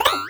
cartoon_boing_jump_05.wav